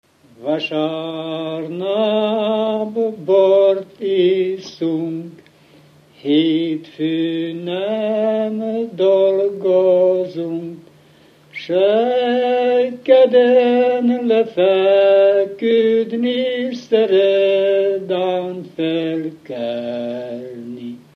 Felföld - Gömör és Kishont vm. - Csucsom
Stílus: 5. Rákóczi dallamkör és fríg környezete
Szótagszám: 6.6.7.6
Kadencia: 4 (4) 4 1